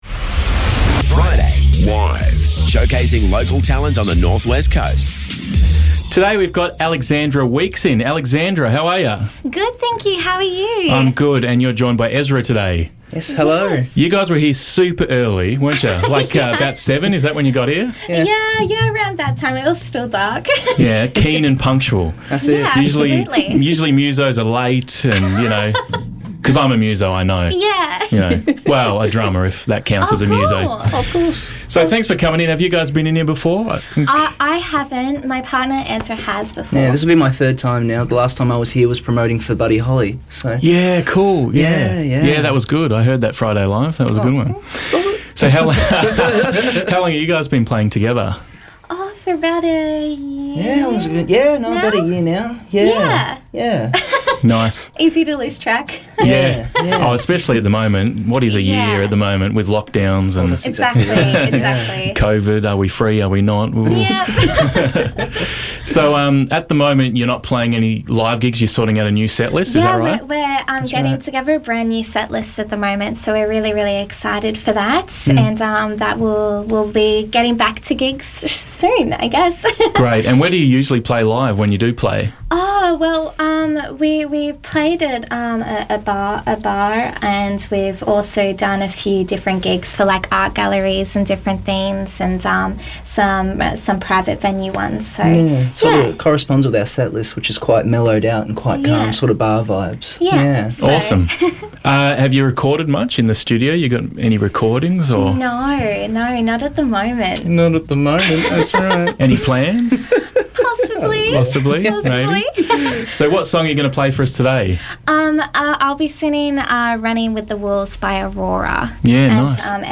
live in the studio